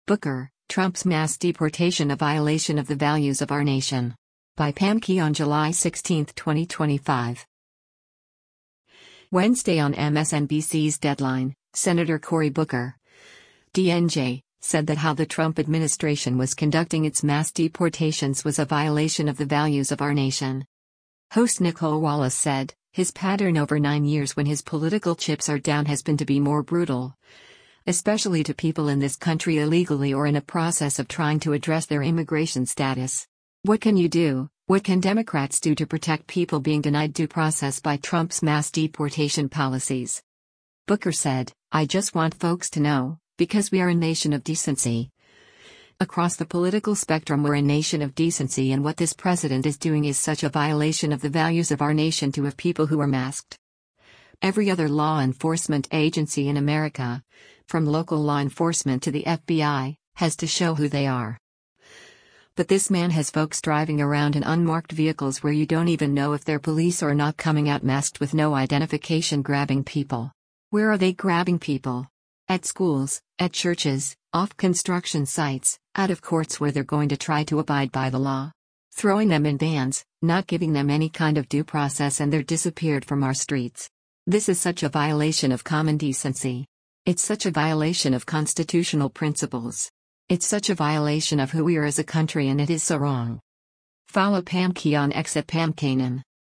Wednesday on MSNBC’s “Deadline,” Sen. Cory Booker (D-NJ) said that how the Trump administration was conducting its mass deportations was a “violation of the values of our nation.”
Host Nicolle Wallace said, “His pattern over nine years when his political chips are down has been to be more brutal, especially to people in this country illegally or in a process of trying to address their immigration status.